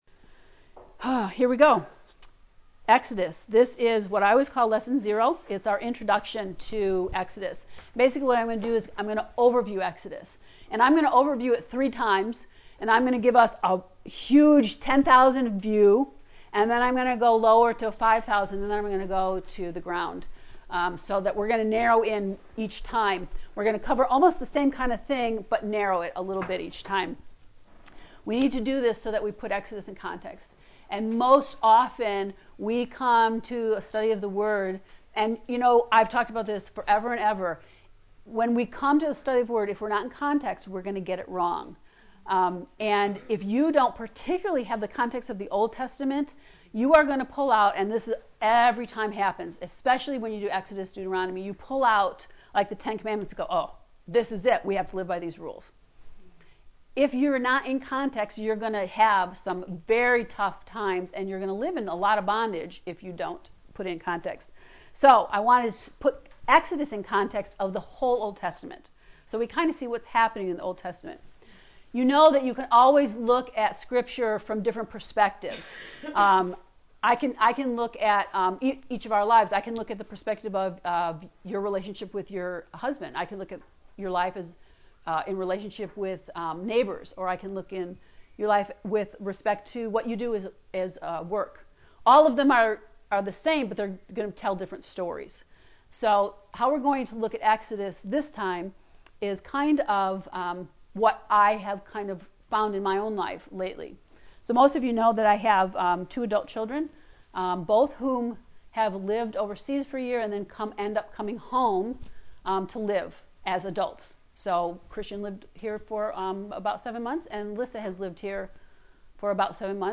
EXODUS lesson 0